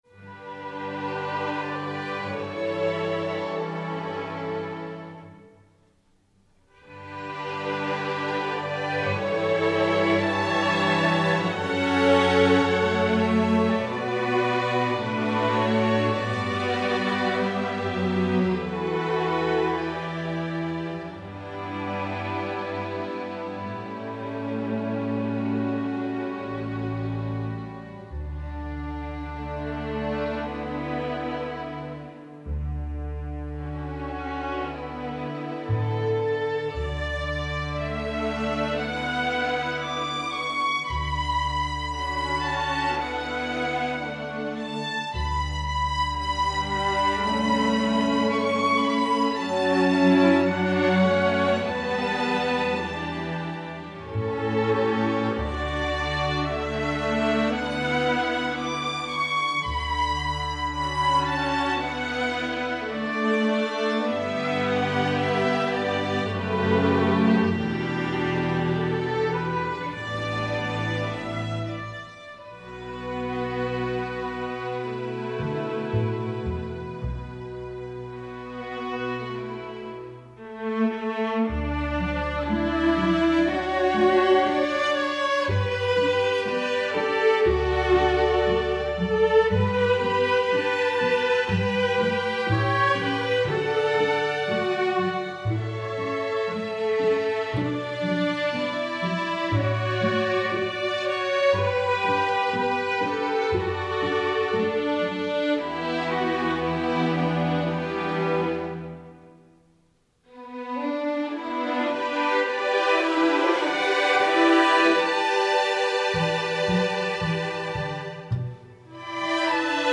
Wisconsin Folk Song
String Orchestra